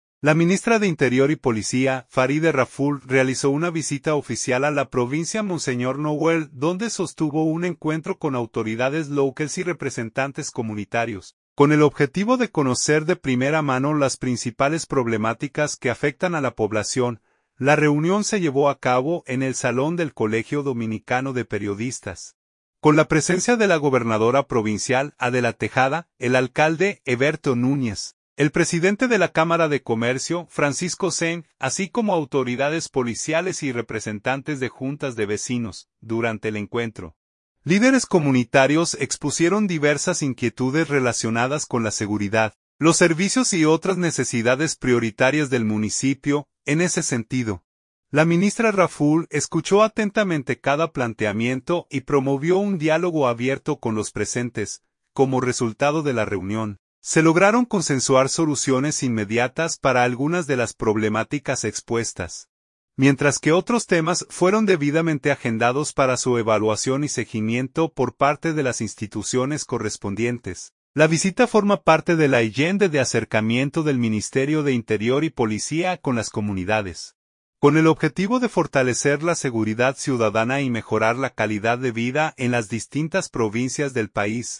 En ese sentido, la ministra Raful escuchó atentamente cada planteamiento y promovió un diálogo abierto con los presentes.